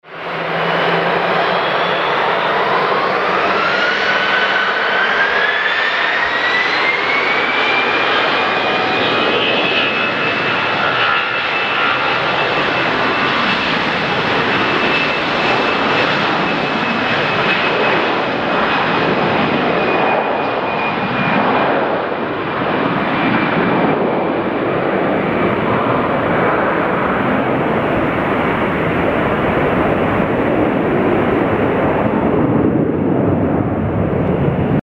Звук взлёта самолёта
Аirbus А320
vzlet-samoleta-airbus-a320.mp3